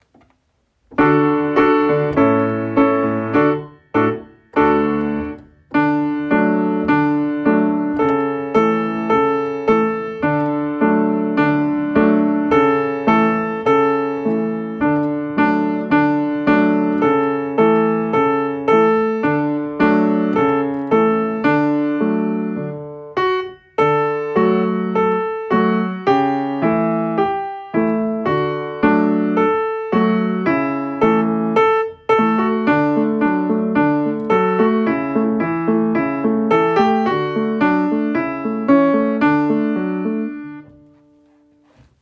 Beispiele Klavier
Das_Konzert___Klavier.mp3